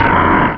Cri de Groret dans Pokémon Rubis et Saphir.